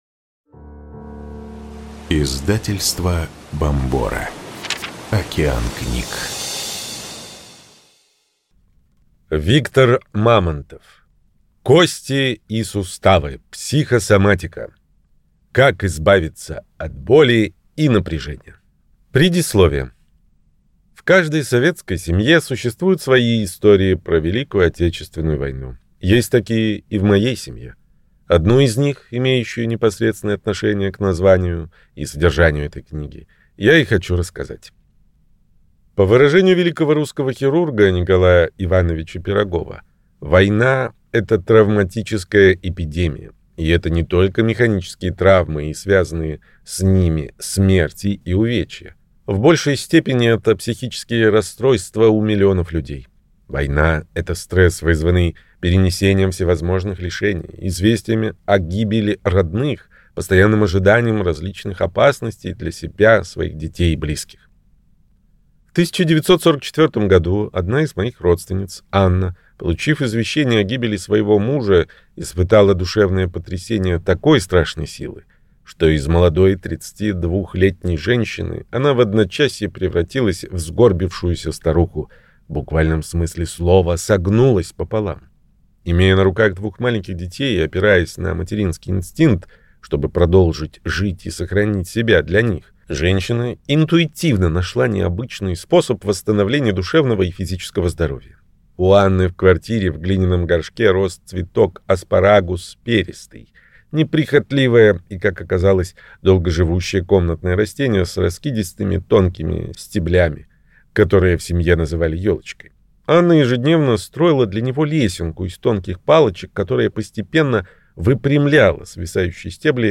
Аудиокнига Кости и суставы: психосоматика. Как избавиться от боли и напряжения | Библиотека аудиокниг